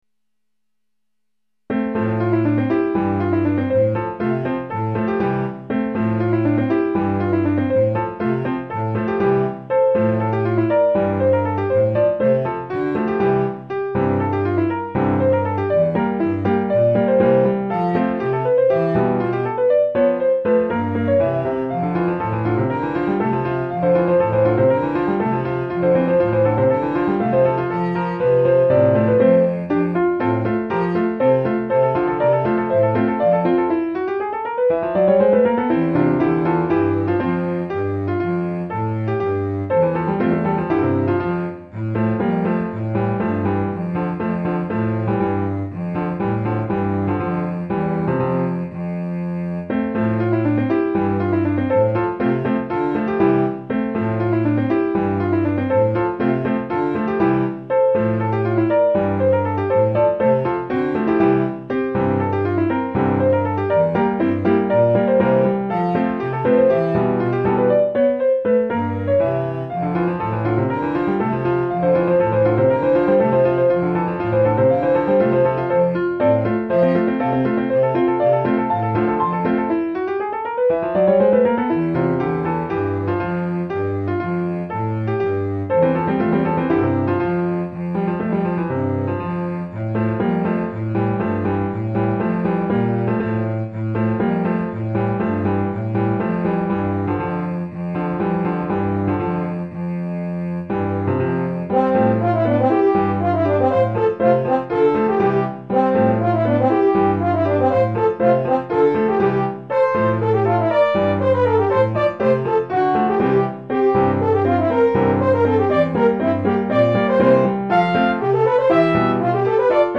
Classical Music Compositions
Op. 12 - Sonata No. 3 for French Horn, Piano, and Cello  Score